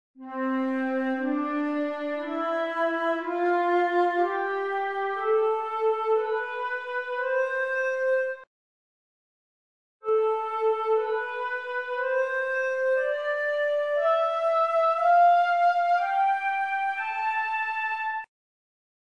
Osserviamo le due scale sullo spartito e ascoltiamole: